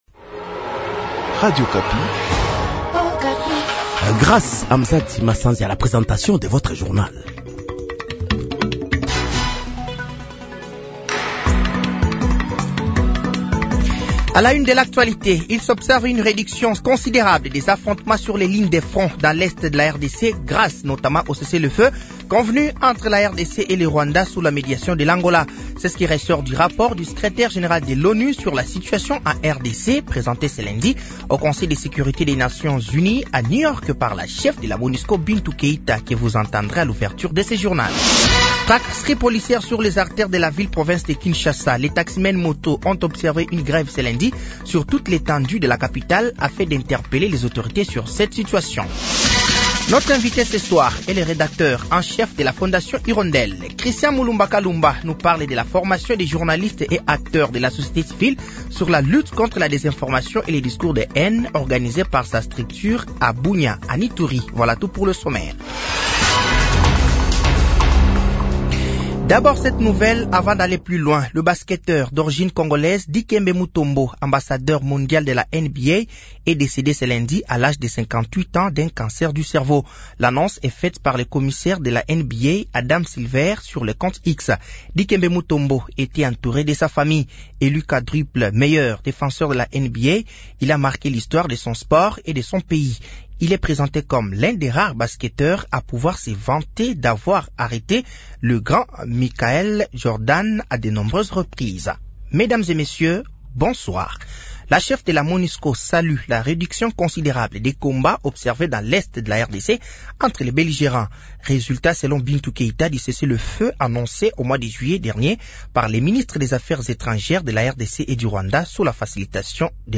Journal français de 18h de ce lundi 30 septembre 2024